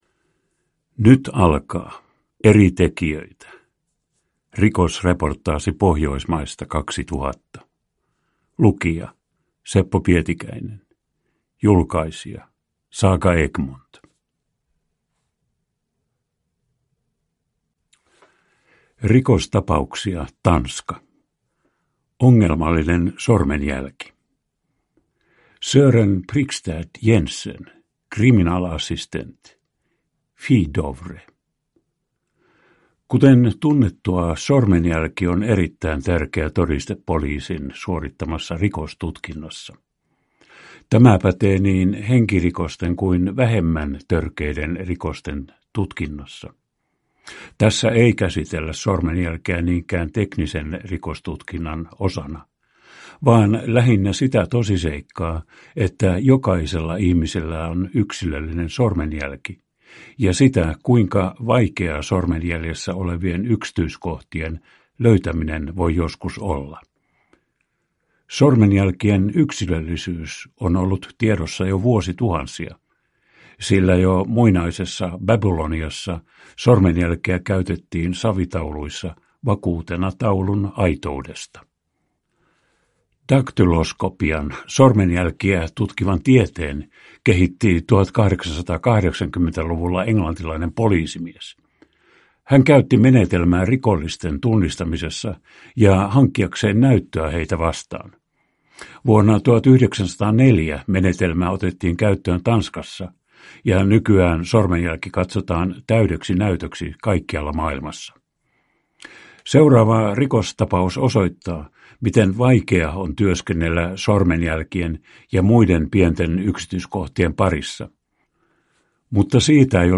Rikosreportaasi Pohjoismaista 2000 (ljudbok) av Eri tekijöitä